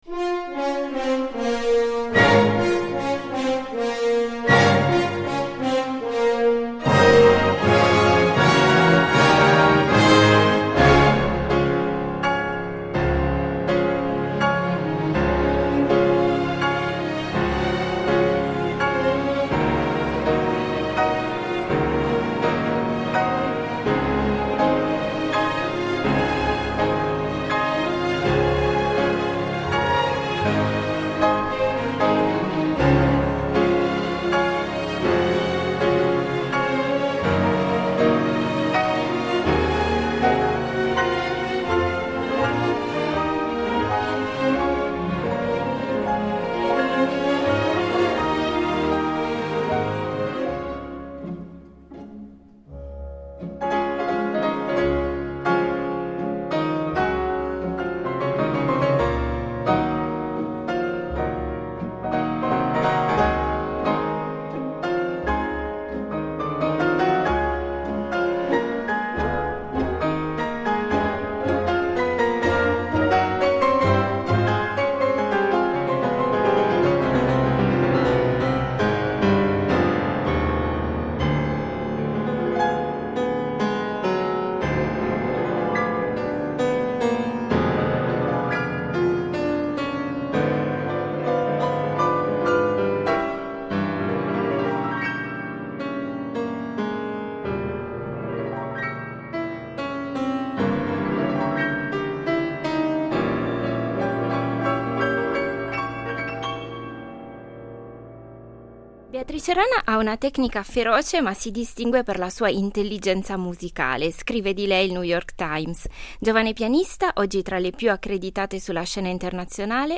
L’abbiamo incontrata, sulle sponde del lago di Lucerna, in occasione della sua partecipazione all’edizione estiva 2025 del Lucerne Festival .